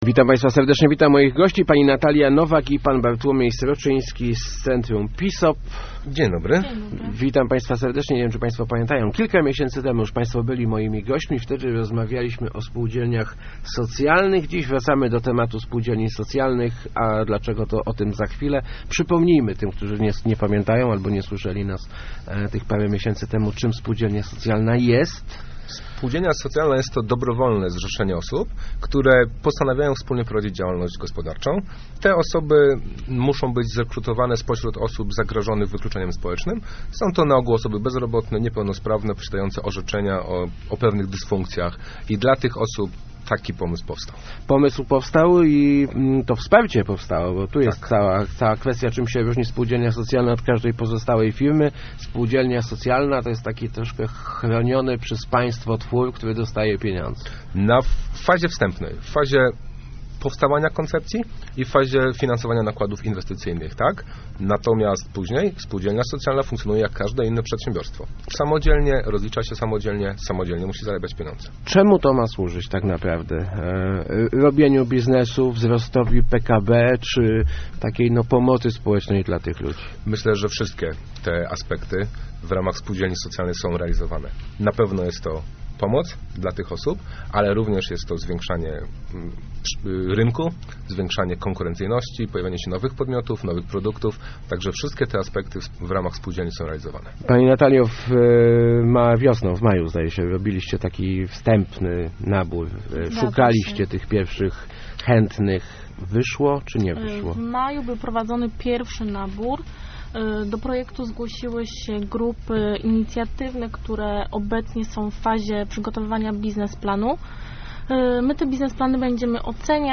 Start arrow Rozmowy Elki arrow Spółdzielnie socjalne coraz popularniejsze